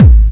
Music/Korg_Electribe